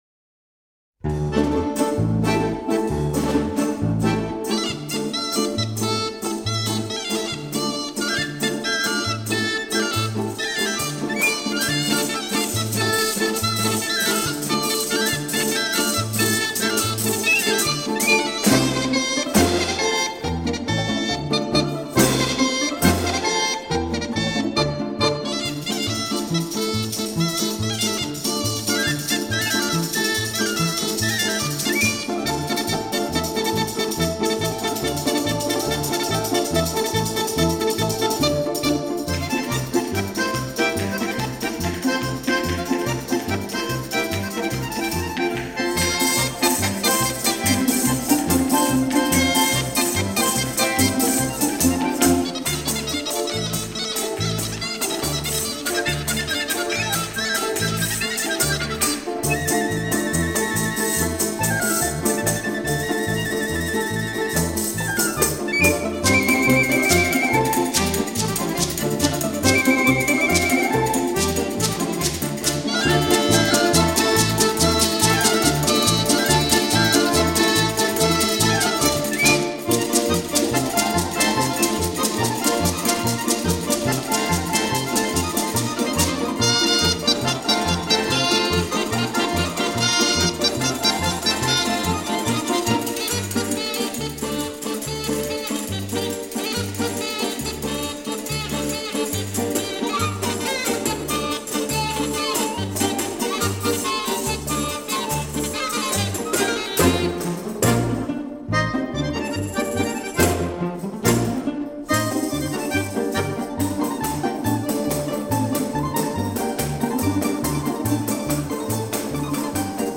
И скорость здесь чуть-чуть другая (у вас слегка забыстрена).